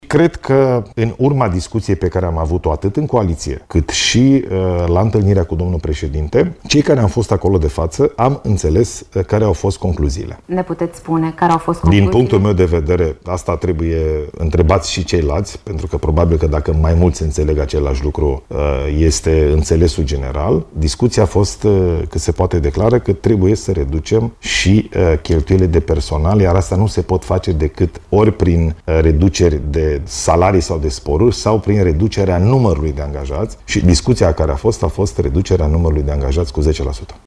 Discuţia la Cotroceni a fost de reducere cu 10% a angajaţilor în administraţia locală, susține premierul Ilie Bolojan. Declarația făcută la postul public de televiziune aseară vine în contextul în care există neînțelegeri în coaliție privind modalitatea în care se se va face reducerea administrației.